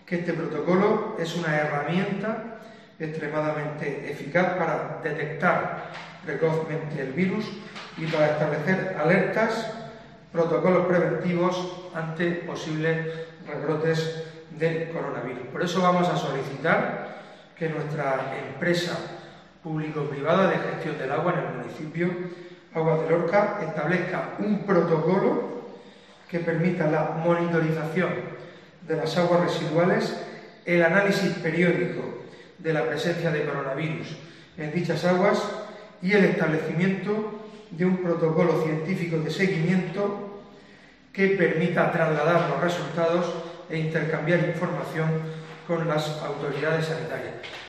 Fulgencio Gil, portavoz del PP en Lorca